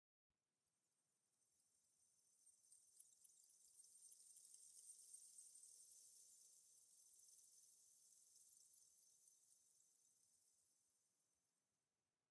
firefly_bush6.ogg